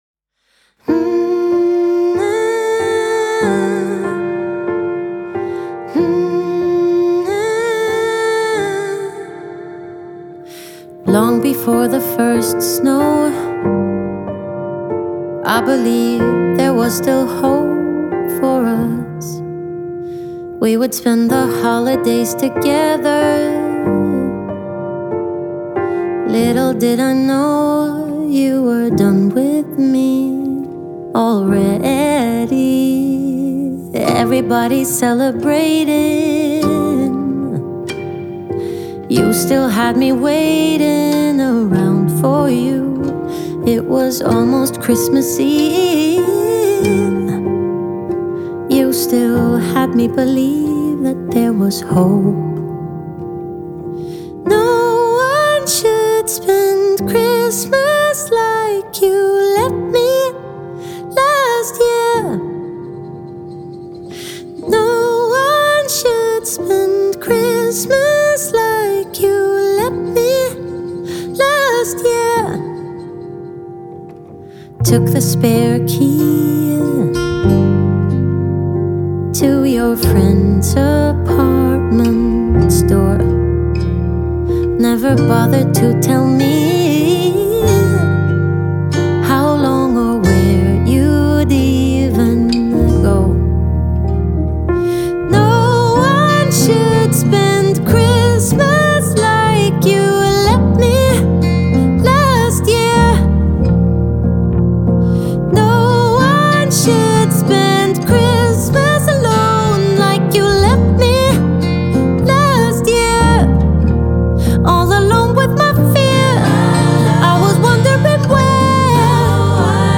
Scandinavian singer-songwriter
poignant ballad